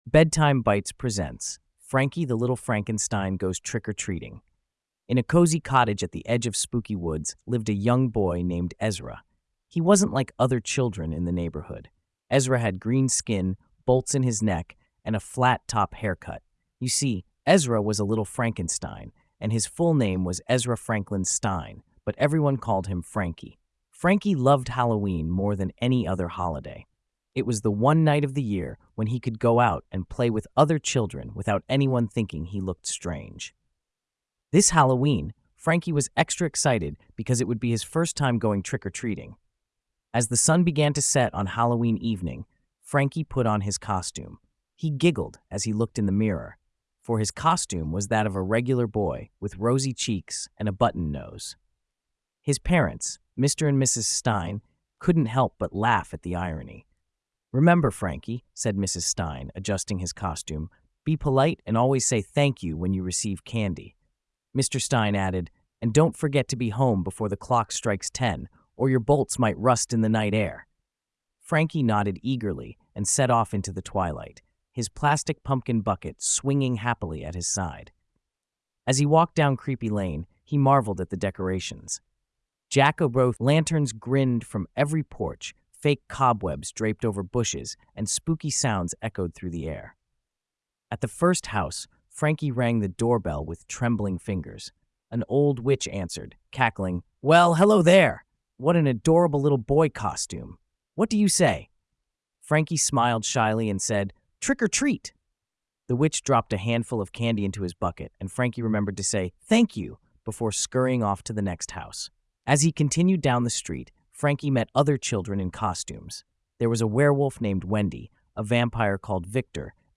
Frankie the Little Frankenstein Goes Trick-or-Treating | Bedtime Bites | Bedtime Stories for Children